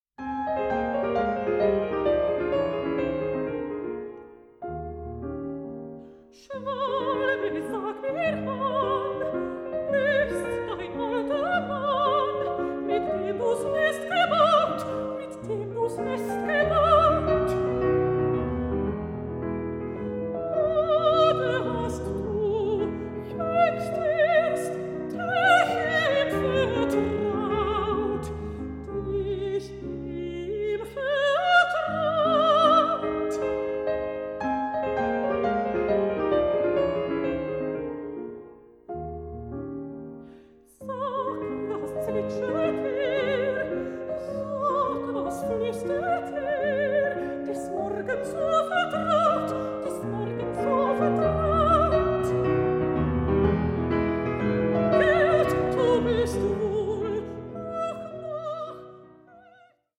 pianist
mezzo-soprano